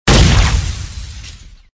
debri explo 2.wav